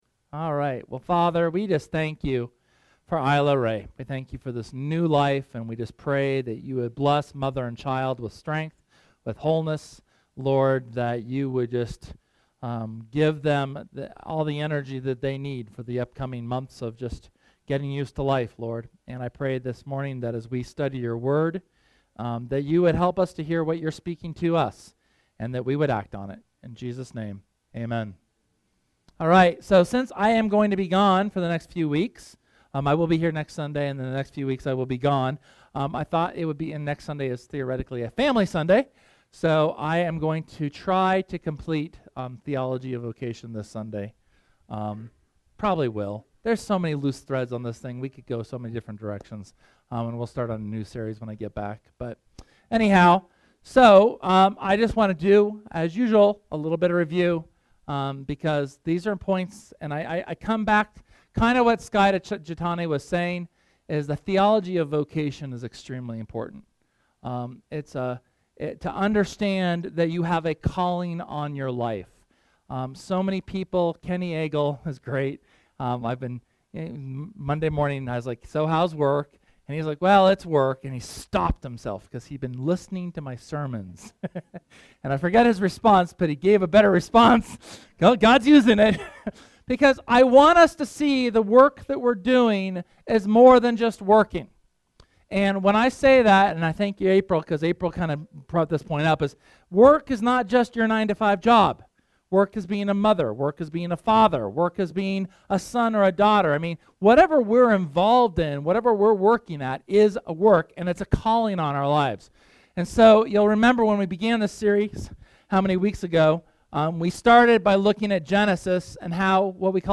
SERMON: Theology of Vocation #5 – Church of the Resurrection